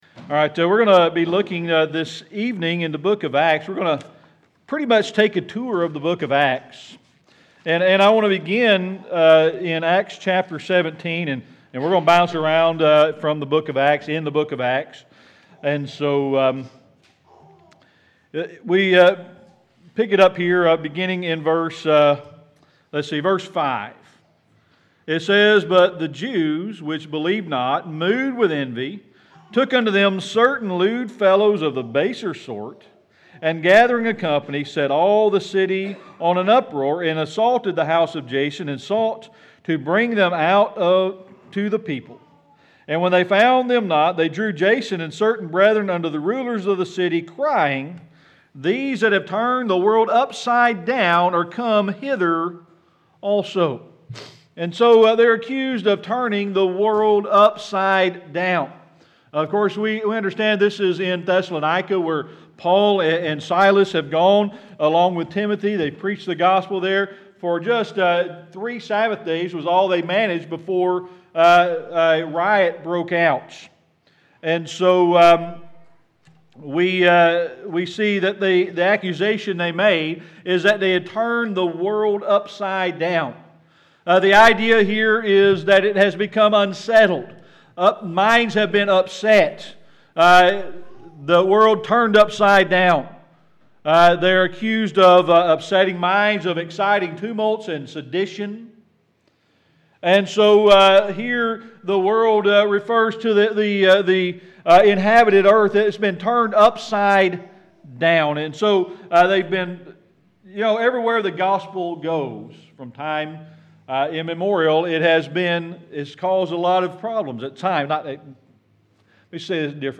Series: Sermon Archives
Acts 17:6 Service Type: Sunday Evening Worship We're going to be looking this evening in the book of Acts.